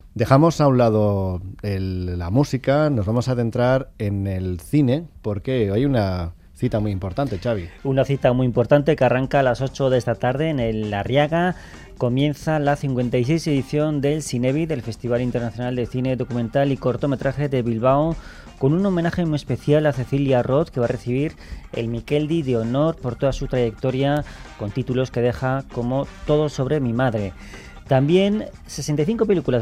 La unidad móvil se adentra en el certamen de cine Zinebi